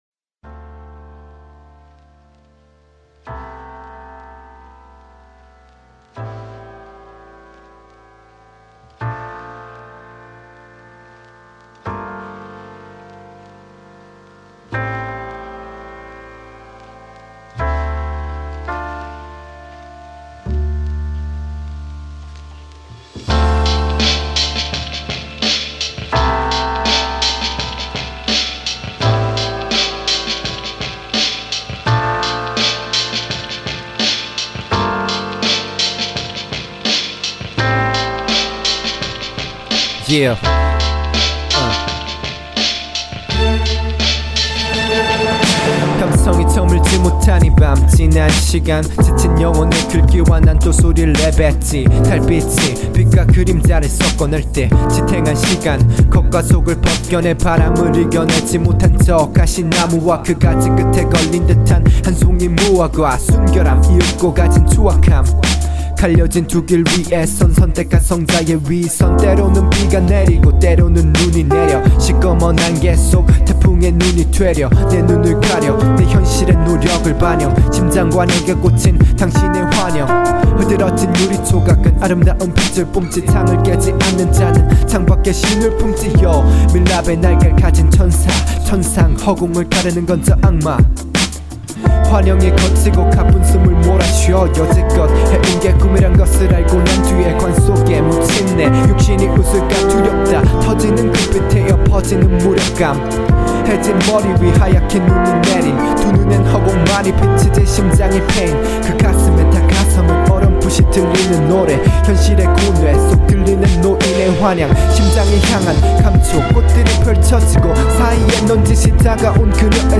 그저 랩이 하고 싶습니다
가사스타일이 되게 시적이시네요 ㅎㅎ
그래도 분위기는 좋내요.